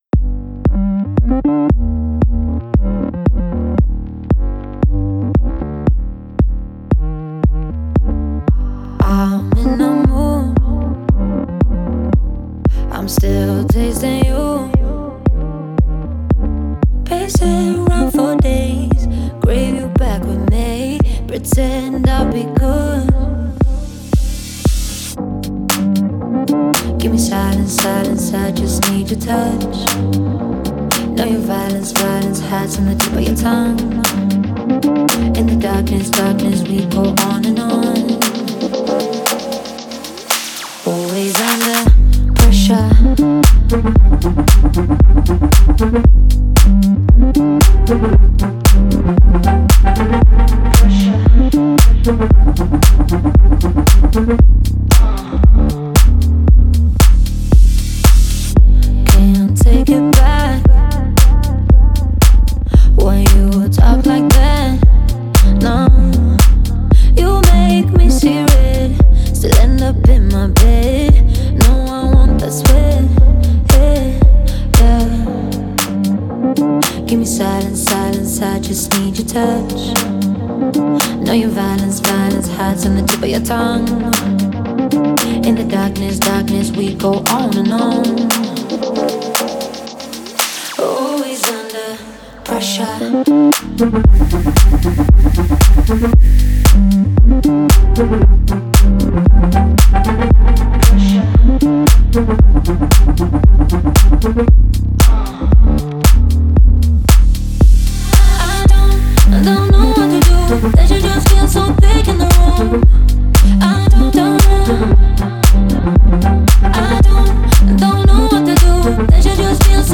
энергичная EDM-композиция